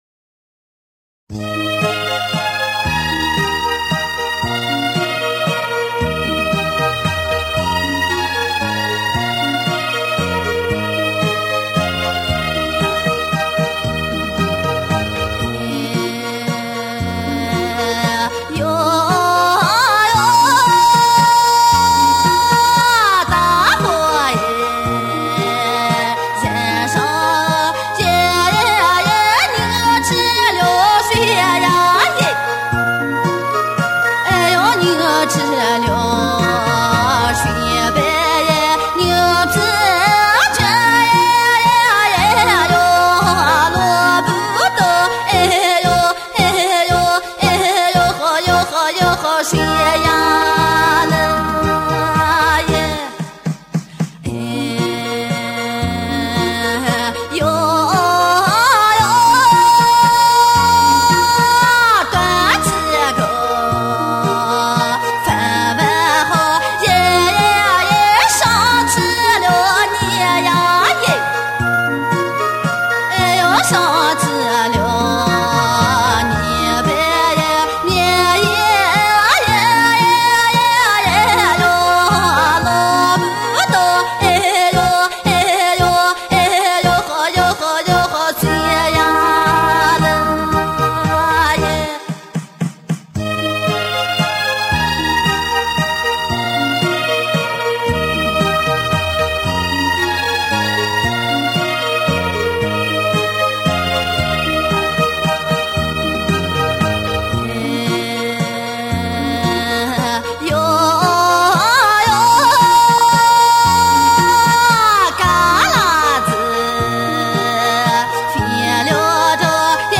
首页 > 图文板块 > 临夏花儿